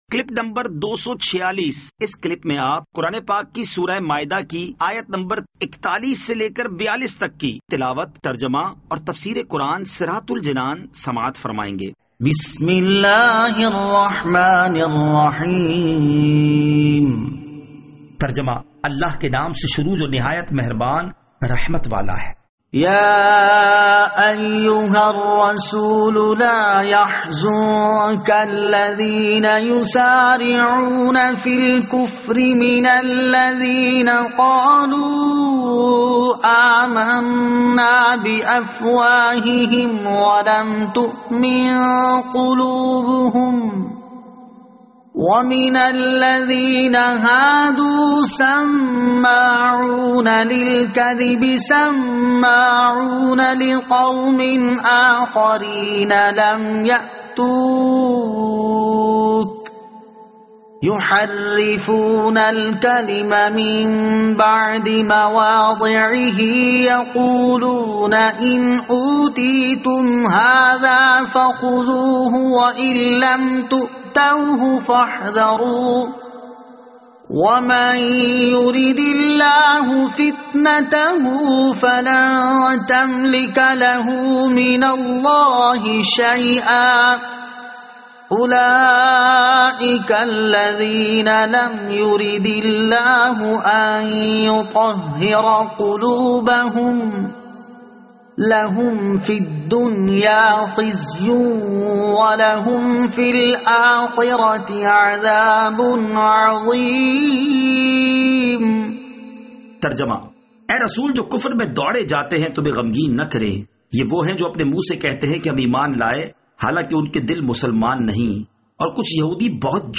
Surah Al-Maidah Ayat 41 To 42 Tilawat , Tarjama , Tafseer